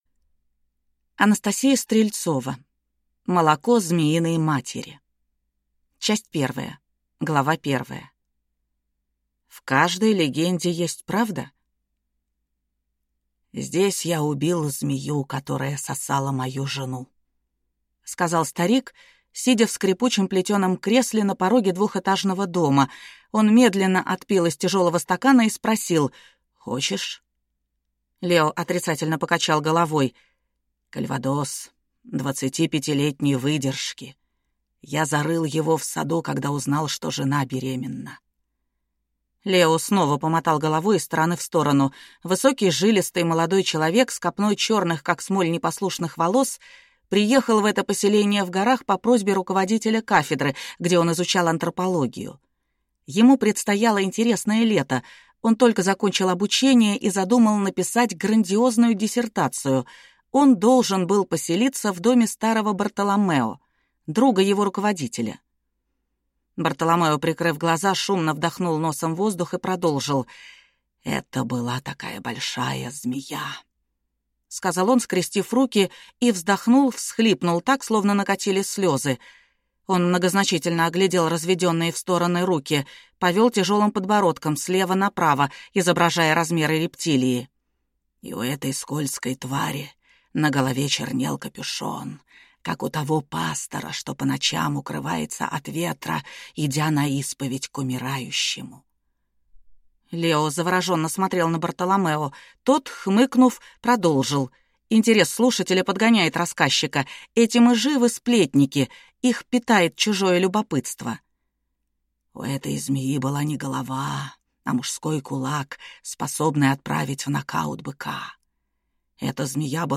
Аудиокнига Молоко змеиной матери | Библиотека аудиокниг